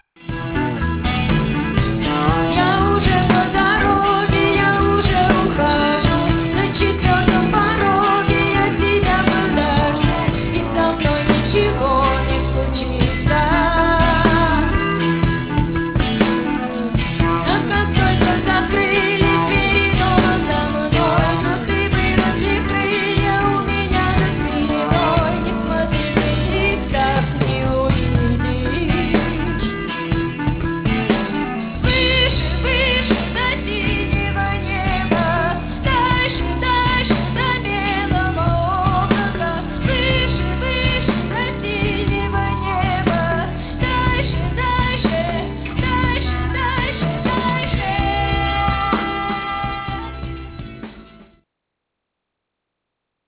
Шаболовка (1995)
фрагмент песни
AUDIO, stereo